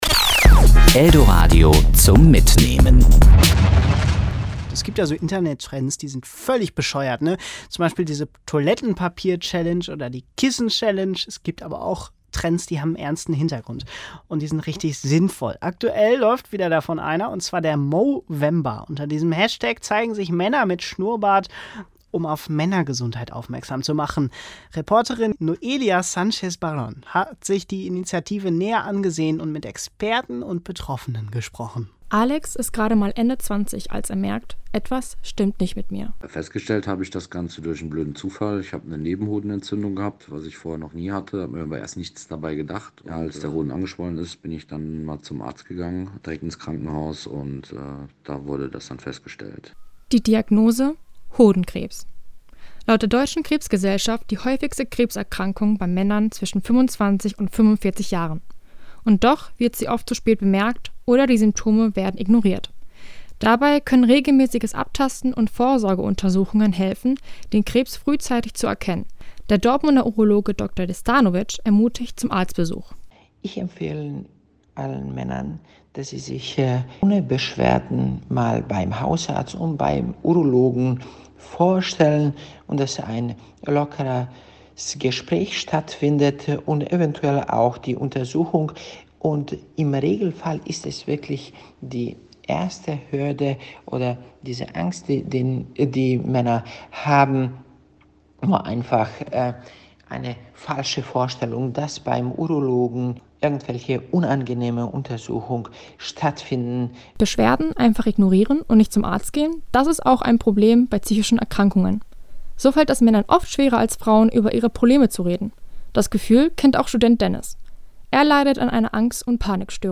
hat mit Betroffenen und Experten über das Thema gesprochen: